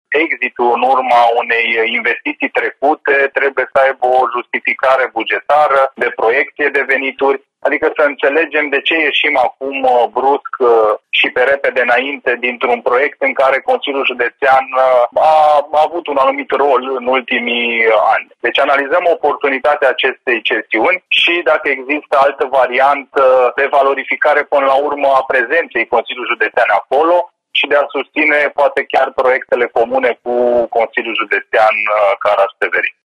Termenul limită de realizarea a studiului este de 30 de zile, dar concluzia comisiei nu va deveni obligatorie, spune consilierul USR, Raul Olajoş, membru în comisia de lucru.